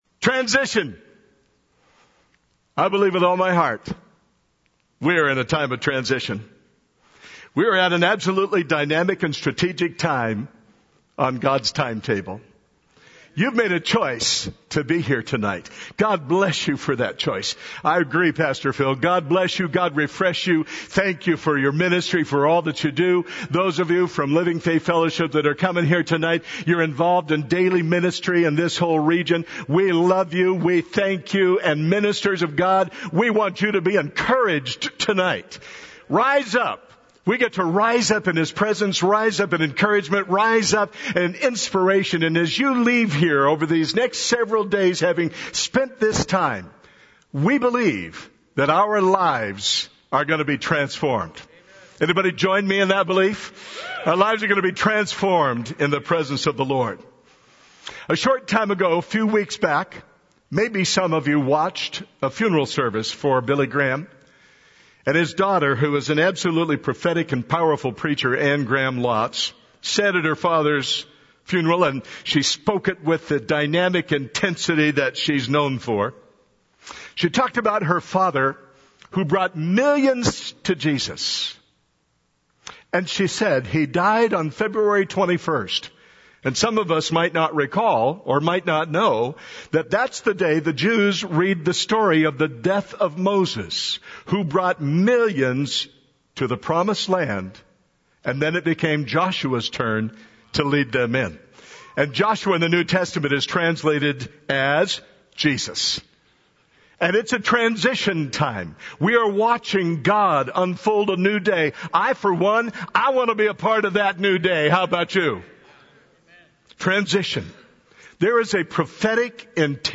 NW Regional Conference of the Fellowship Network - Rise Up!